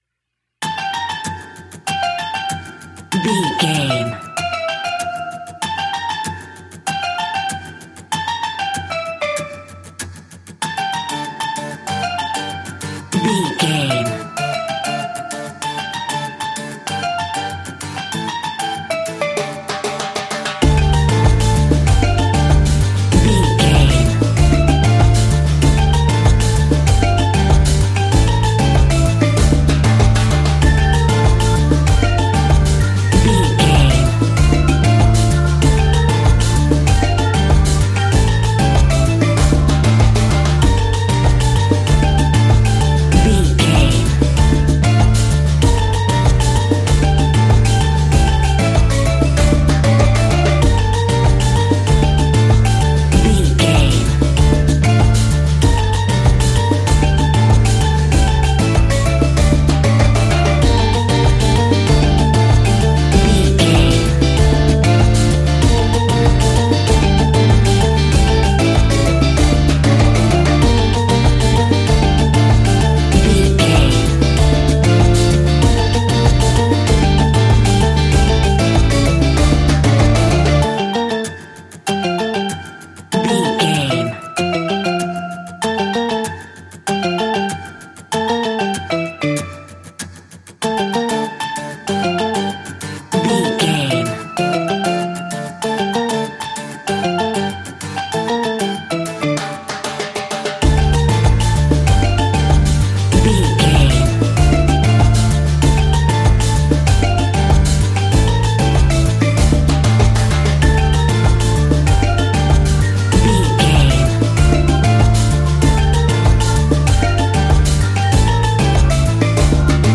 Aeolian/Minor
steelpan
drums
brass
guitar